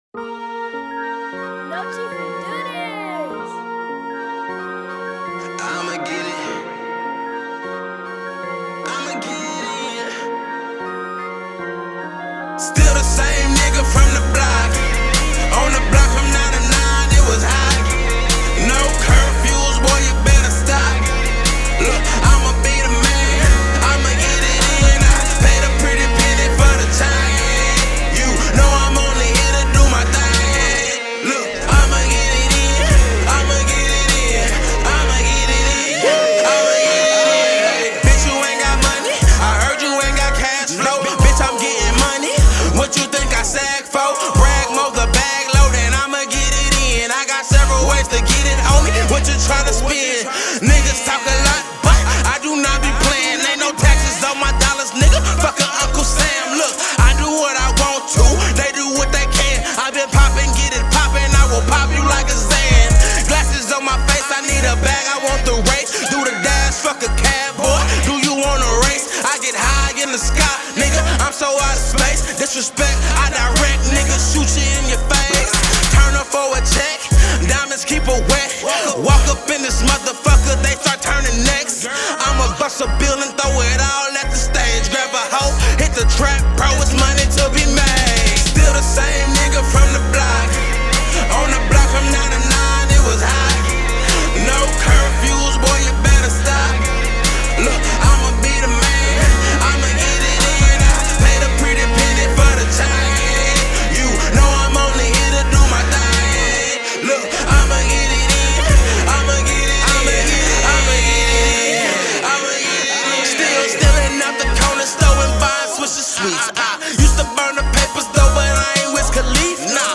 Hiphop
is sure to make a head or two nod and bob